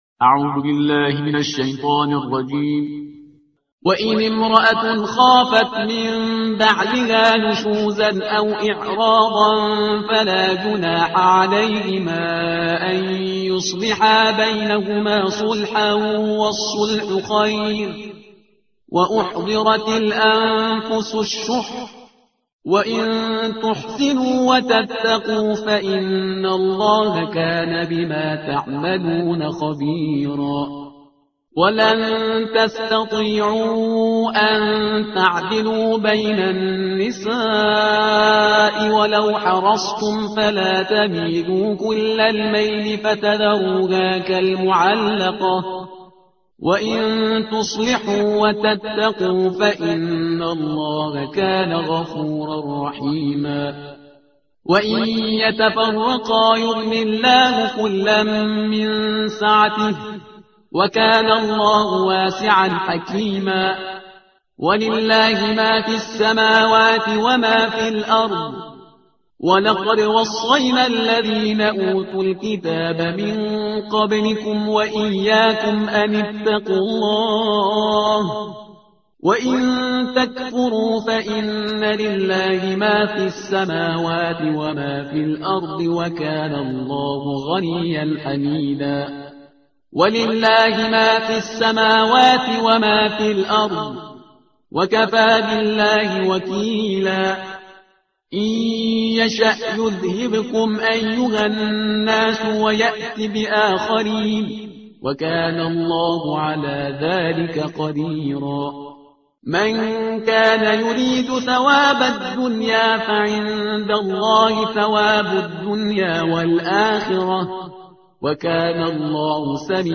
ترتیل صفحه 99 قرآن